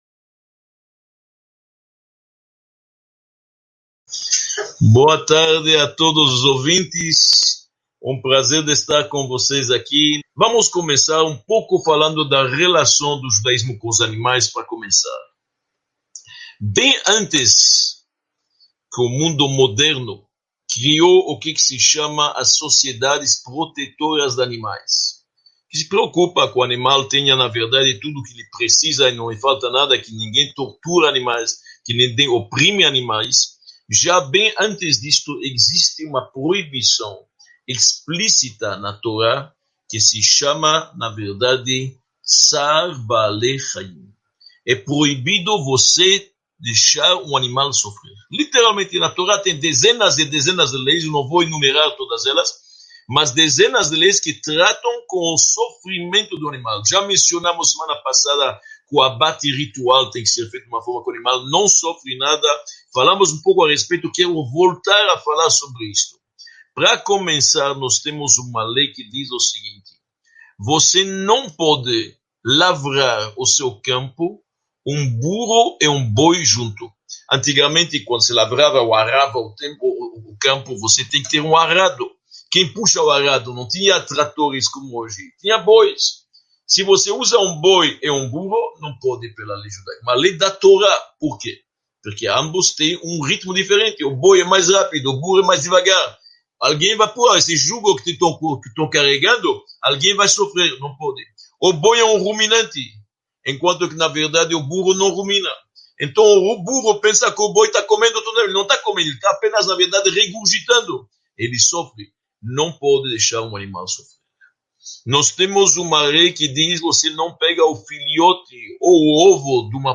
08 – Animais, aves e peixes | Os Mistérios do Universo – Aula 08 | Manual Judaico